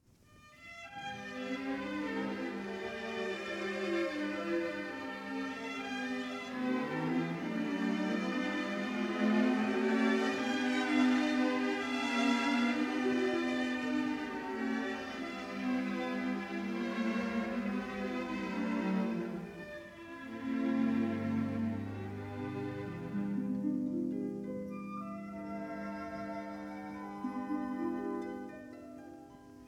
stereo recording